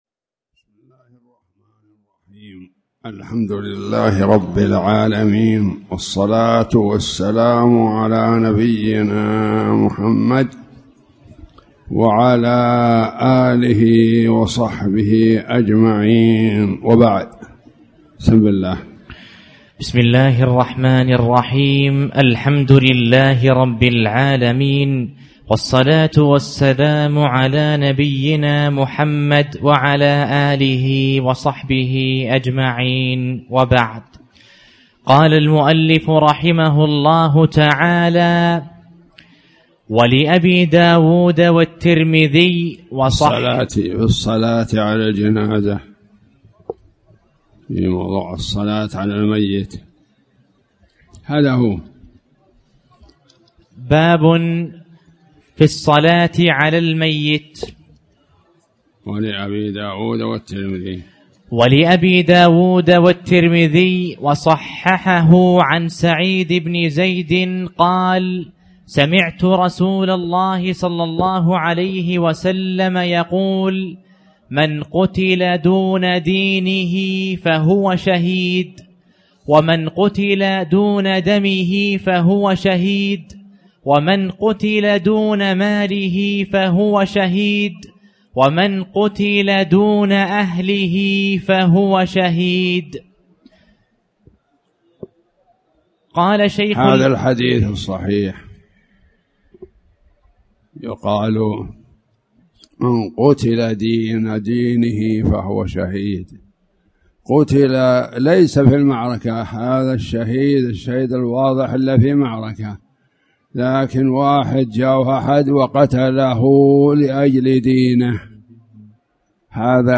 تاريخ النشر ٢٤ محرم ١٤٣٩ هـ المكان: المسجد الحرام الشيخ